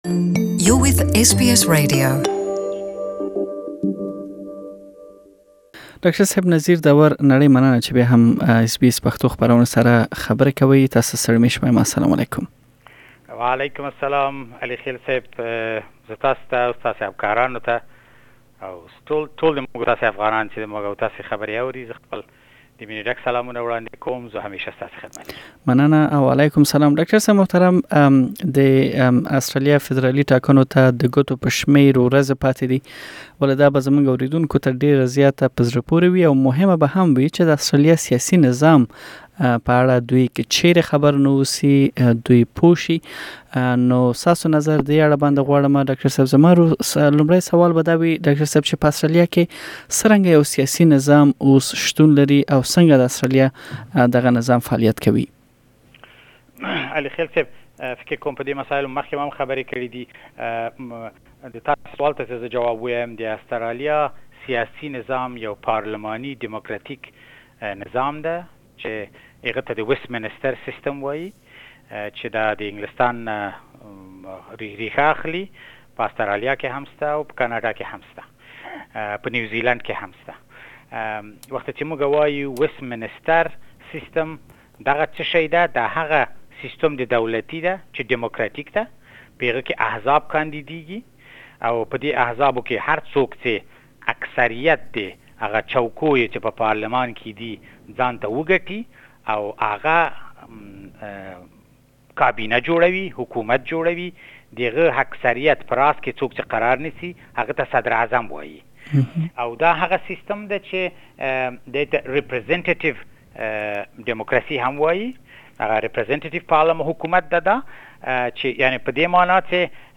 بشپړه مرکه دلته واورئ.